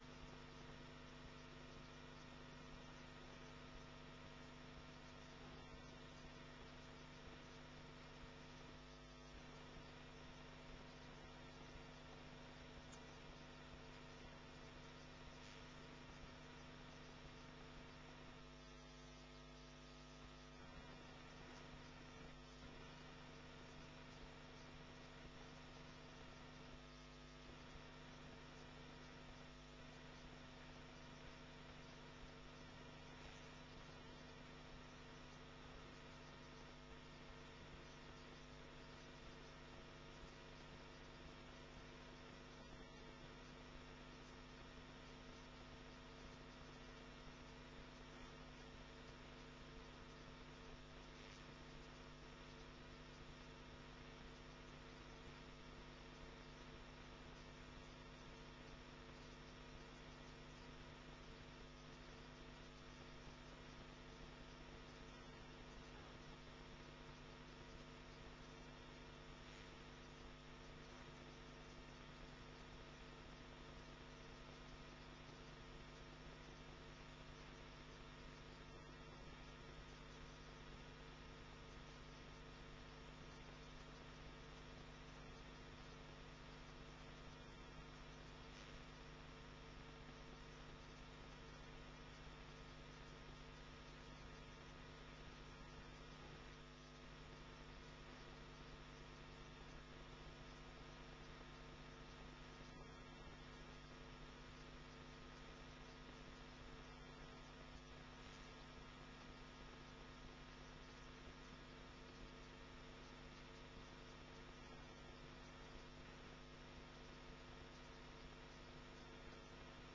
Gemeenteraad 28 oktober 2009 19:30:00, Gemeente Renkum
Locatie: Raadzaal